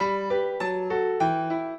piano
minuet9-7.wav